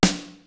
SNARE#3002.wav